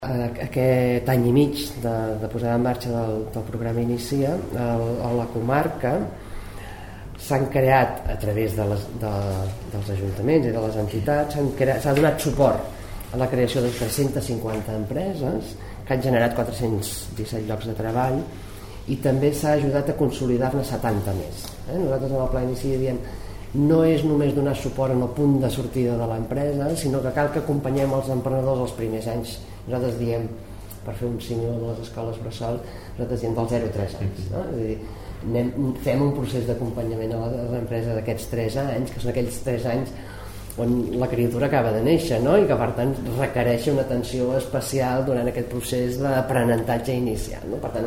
Tall de veu Mireia Franch, directora general d'Economia Cooperativa i Creació d'Empreses